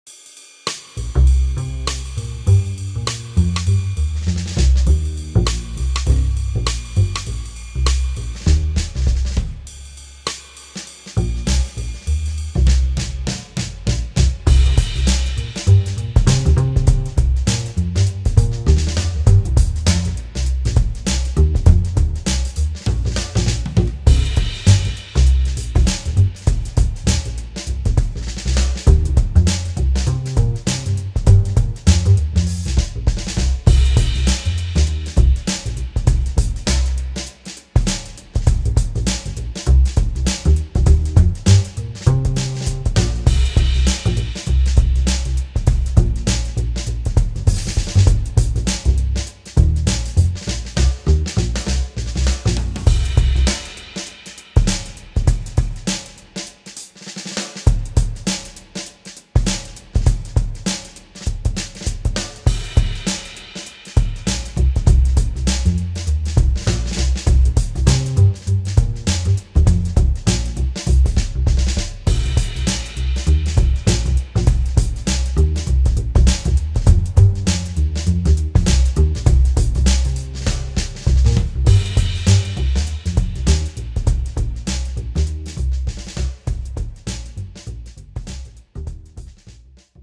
【声劇】
Jazz Style Rythm Track